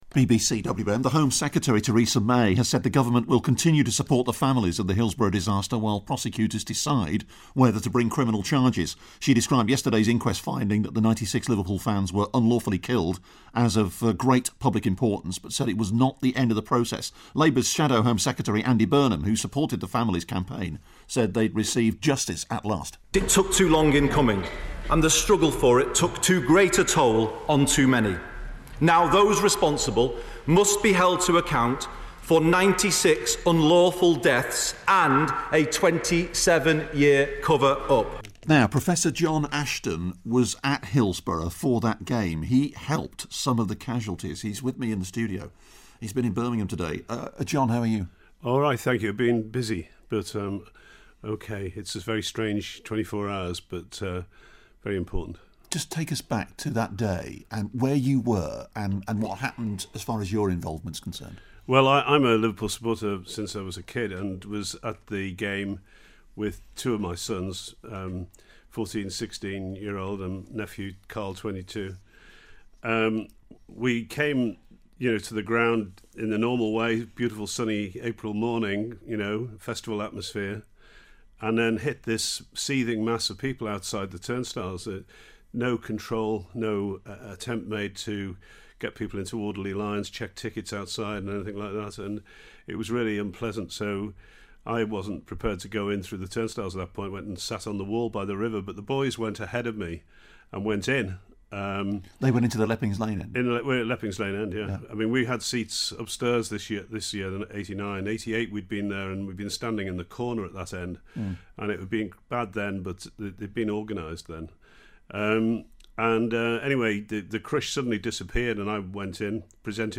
interviews doctor who helped Hillsborough casualties